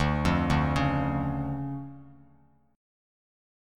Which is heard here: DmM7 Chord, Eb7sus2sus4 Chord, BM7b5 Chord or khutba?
DmM7 Chord